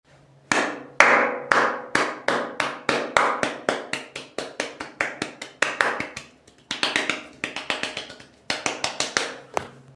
Slaps! Efecto de Sonido Descargar
Slaps! Botón de Sonido